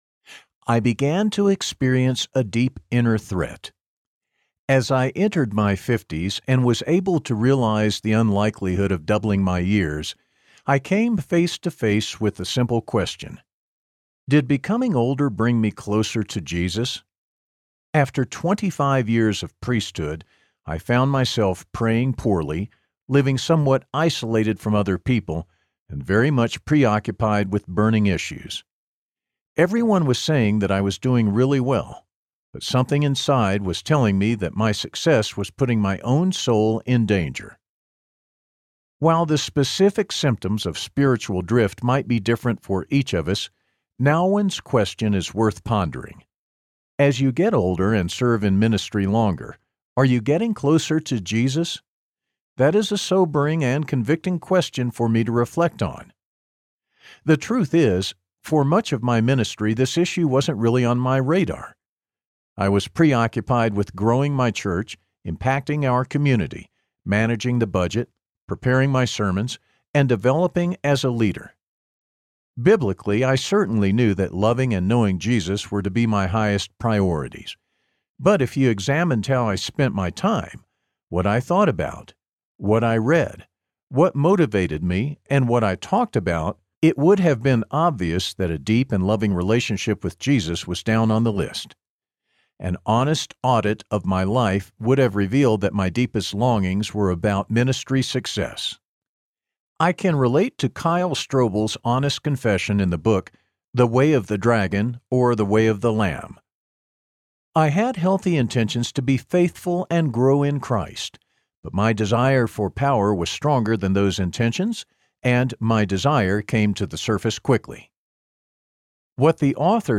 High Impact Teams Audiobook
Narrator
9.10 Hrs. – Unabridged